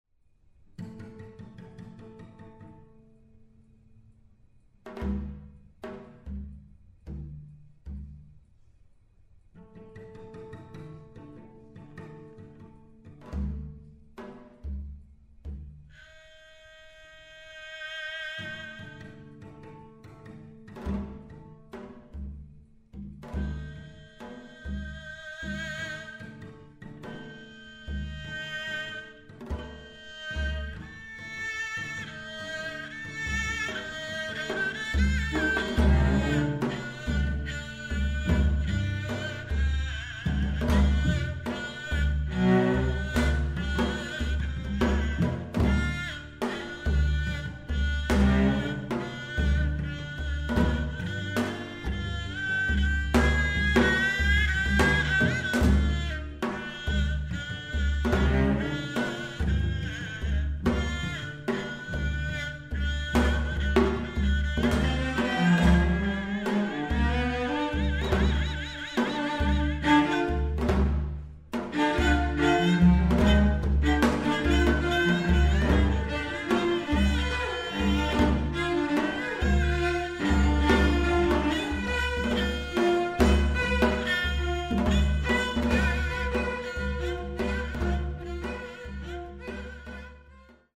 haegeum, cello, janggu